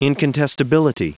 Prononciation du mot incontestability en anglais (fichier audio)
Prononciation du mot : incontestability